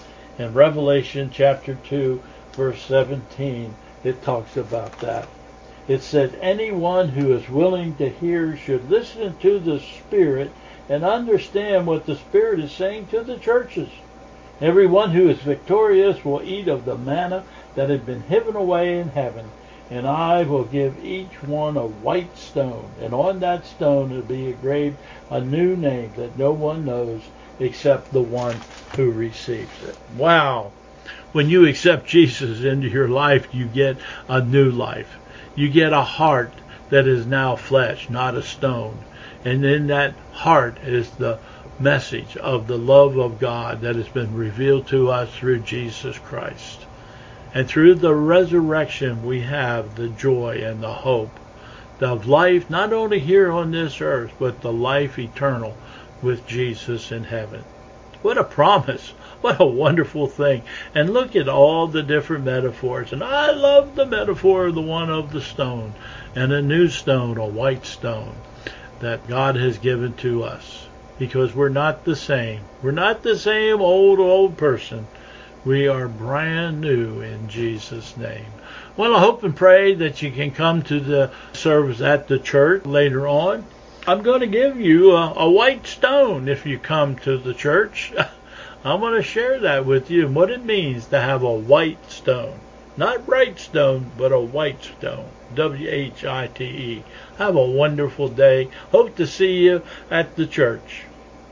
The audio recordings are from the online sunrise service.